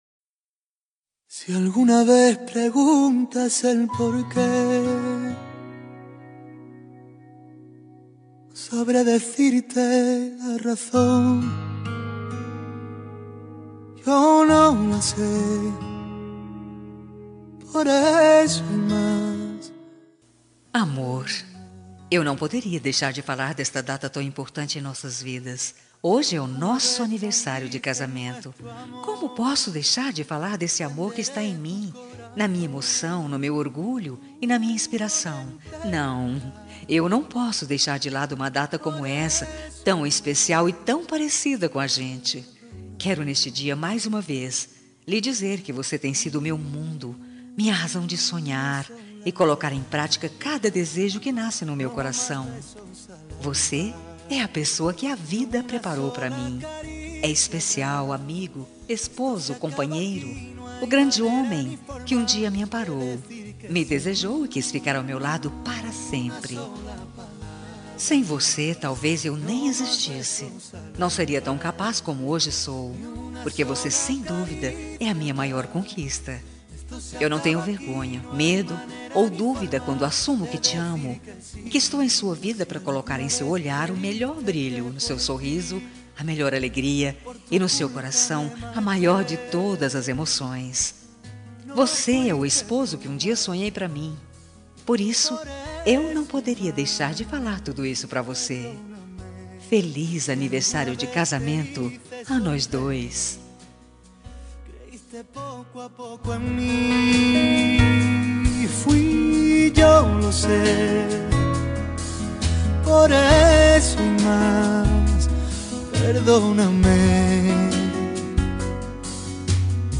Aniversário de Casamento – Voz Feminina – Cód: 8110 – Linda.